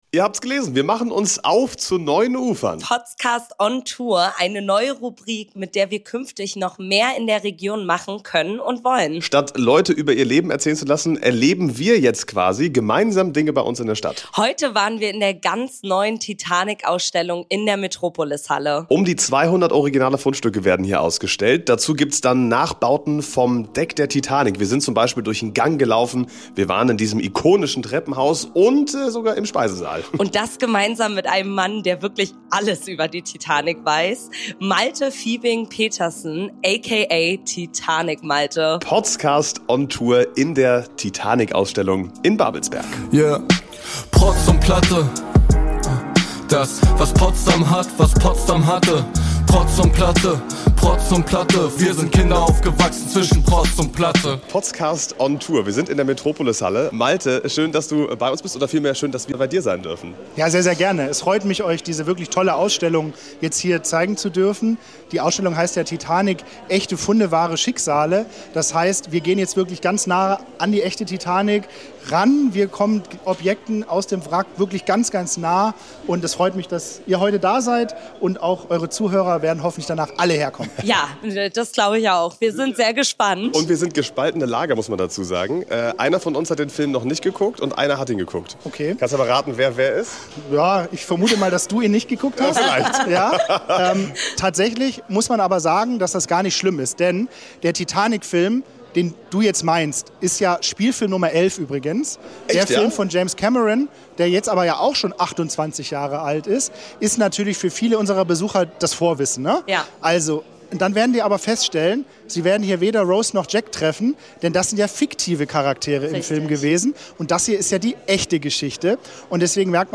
Beschreibung vor 4 Monaten Mit „ON TOUR“ starten wir eine neue Rubrik: Wir sind in Potsdam unterwegs und besuchen die unterschiedlichsten Events. Zum Start: Die neue Titanic-Ausstellung in der Metropolis-Halle in Babelsberg.